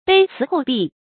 卑辭厚幣 注音： ㄅㄟ ㄘㄧˊ ㄏㄡˋ ㄅㄧˋ 讀音讀法： 意思解釋： 指言辭謙恭，禮物豐厚。